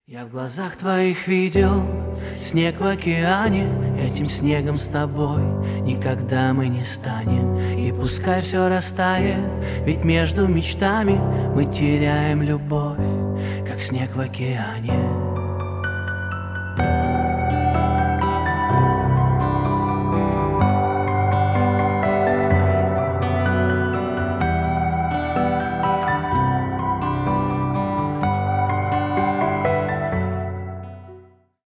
• Качество: 320 kbps, Stereo
Поп Музыка
грустные